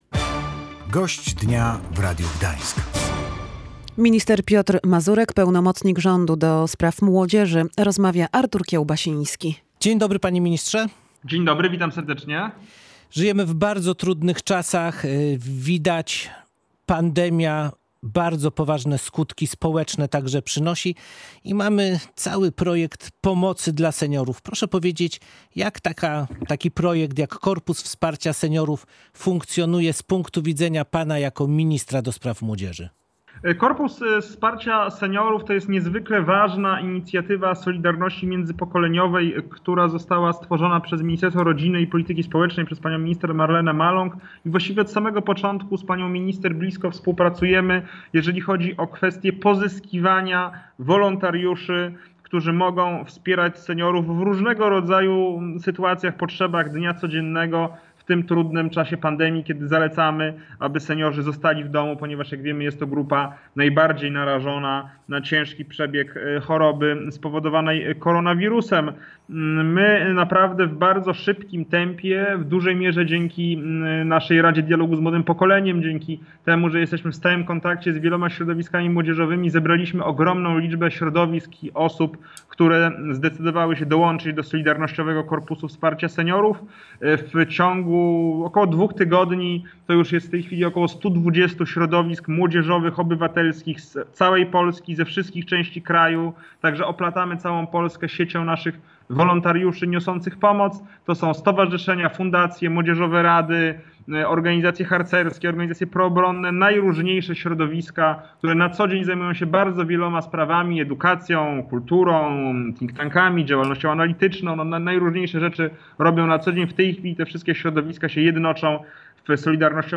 Został powołany, by pomagać osobom w podeszłym wieku i samotnym w czasie pandemii. Zgłosiło się już około 120 środowisk i organizacji z całej Polski, w tym osiem tysięcy wolontariuszy – mówił w Radiu Gdańsk minister Piotr Mazurek, pełnomocnik rządu ds. młodzieży.